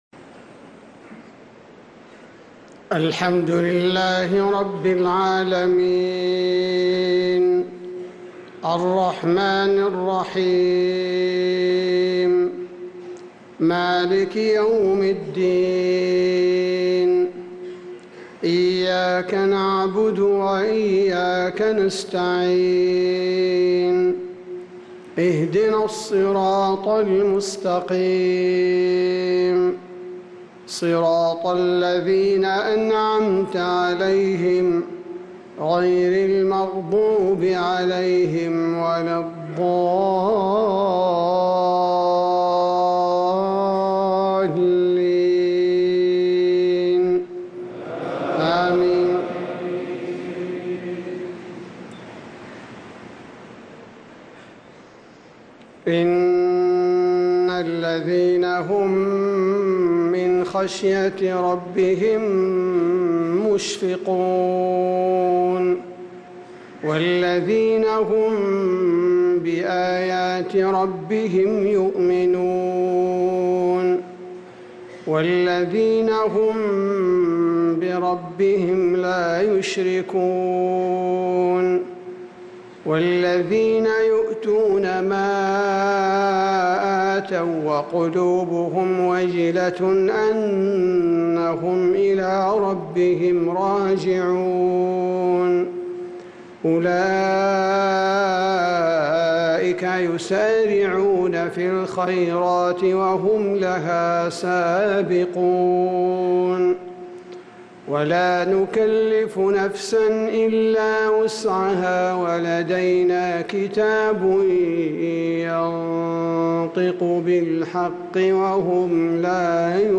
إصدار جميع تلاوات الشيخ عبدالباري الثبيتي في شهر محرم - صفر 1446هـ > سلسلة الإصدارات القرآنية الشهرية للشيخ عبدالباري الثبيتي > الإصدارات الشهرية لتلاوات الحرم النبوي 🕌 ( مميز ) > المزيد - تلاوات الحرمين